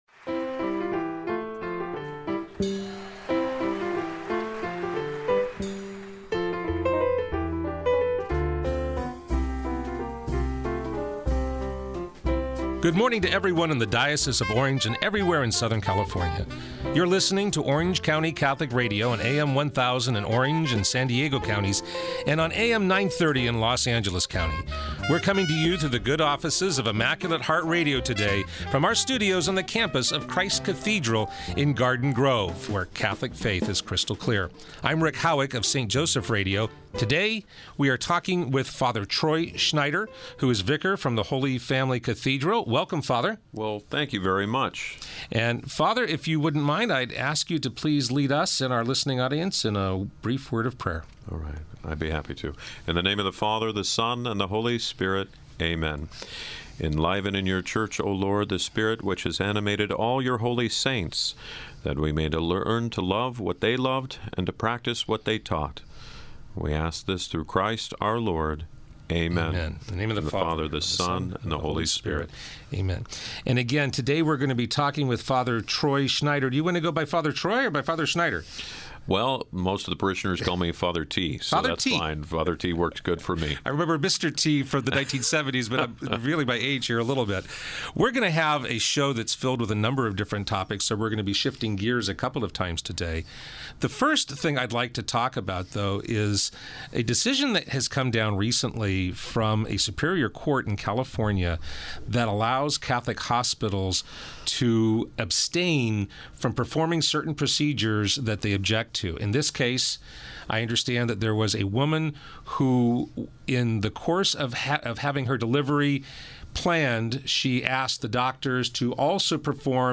interviews guests on a variety of topics.